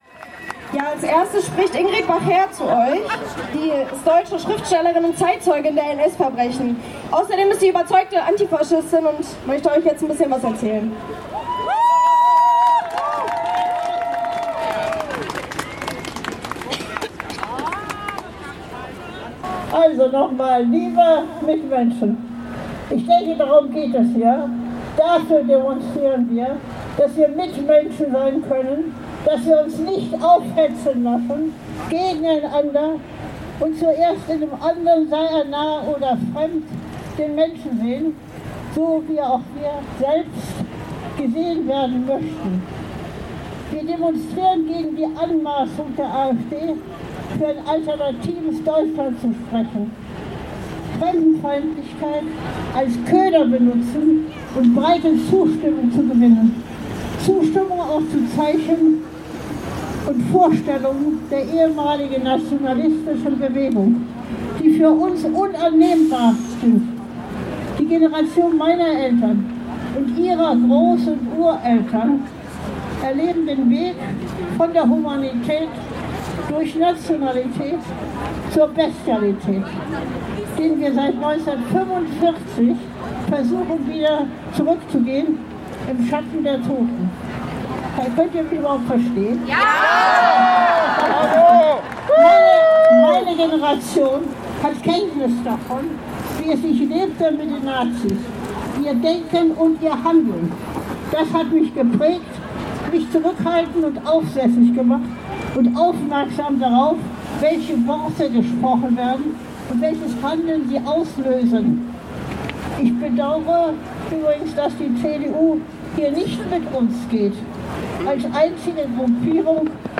Düsseldorf stellt sich quer: Demonstration „Gegen die AfD und die Rechtsentwicklung der Gesellschaft“ (Audio 4/16)
Ingrid Bachér trug ihre Rede „Unannehmbar“ vor.
Hinweis: Da die akustischen Rahmenbedingungen bei der Ansprache von Ingrid Bachér sehr schlecht waren, gibt es hier eine Transkription ihrer Rede „Unannehmbar“[100] zum nachlesen.